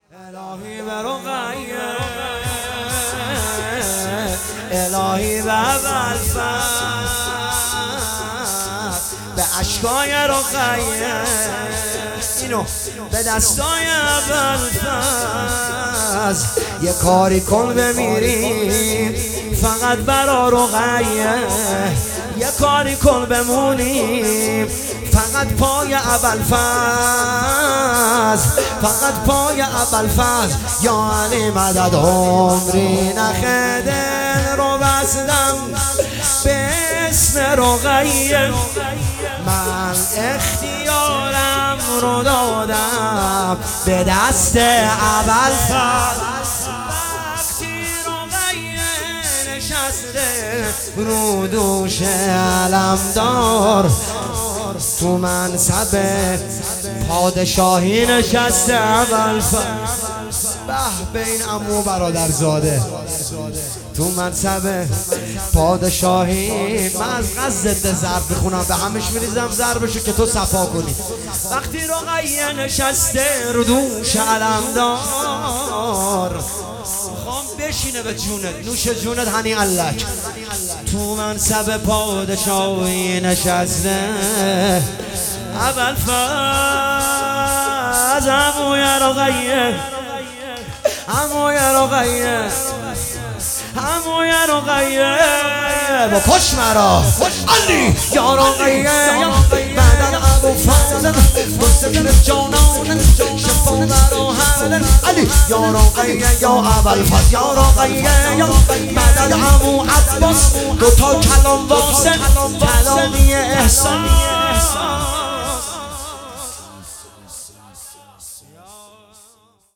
دانلود فایل صوتی مداحی شور حضرت رقیه
جلسه هفتگی اردیبهشت 1404